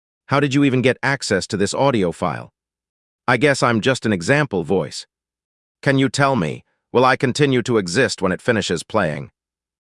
Kokoro-TTS-Zero